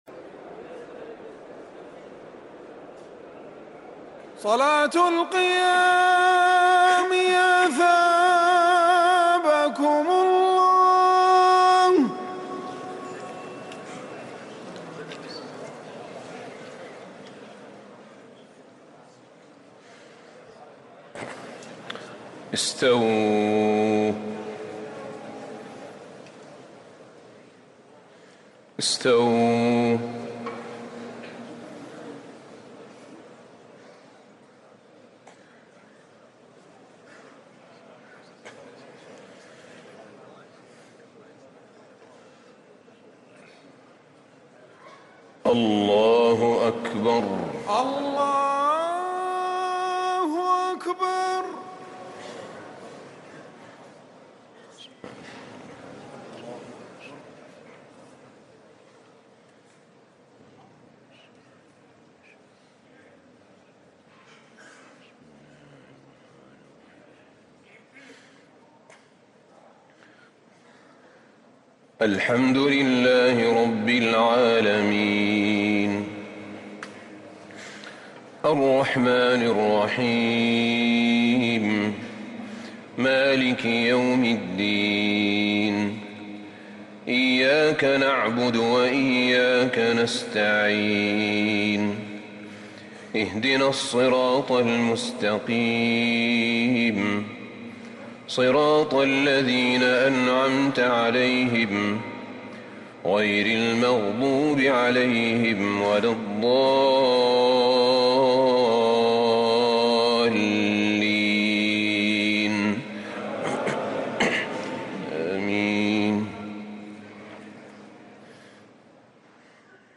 تهجد ليلة 26 رمضان 1444هـ سورة الشورى (15-35) الزخرف الدخان | Tahajjud 26 st night Ramadan 1444H Al-Shura & Az-Zukhruf & AdDukhan > تراويح الحرم النبوي عام 1444 🕌 > التراويح - تلاوات الحرمين